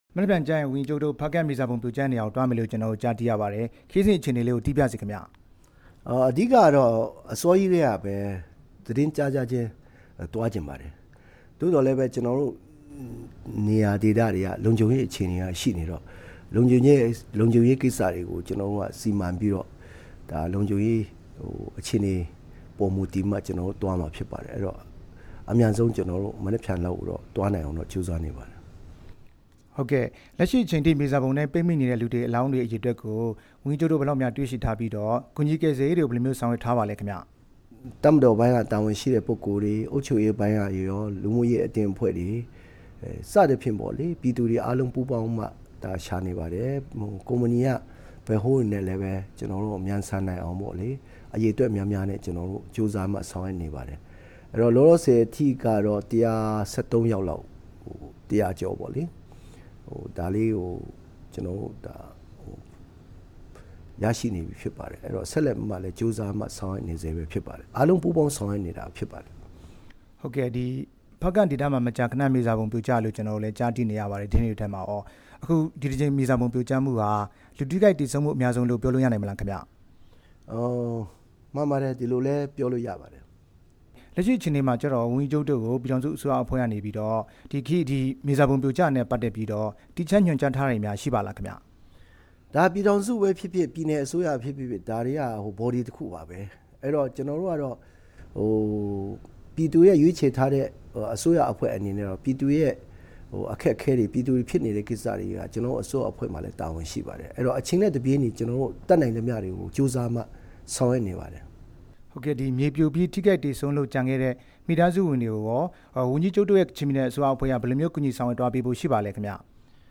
ဖားကန့် မြေစာပုံပြိုကျမှု ဦးလဂျွန်ငန်ဆိုင်းနဲ့ မေးမြန်းချက်